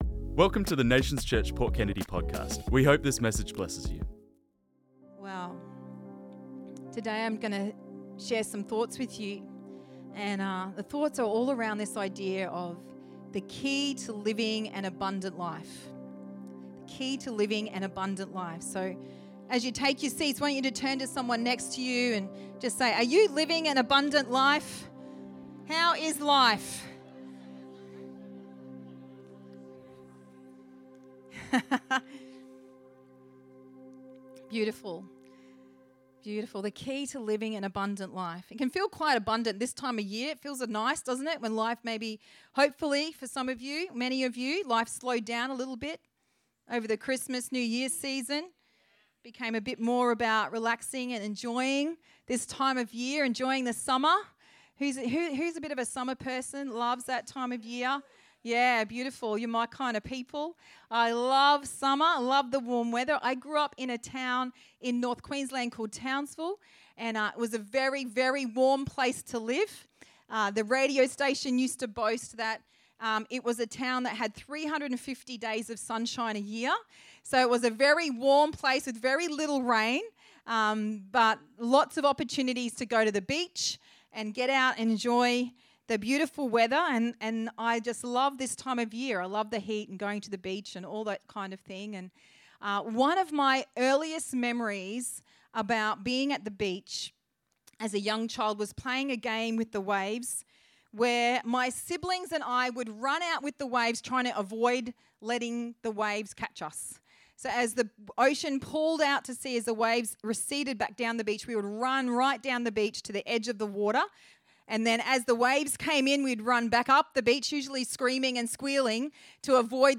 This message was preached on Sunday 5th January 2025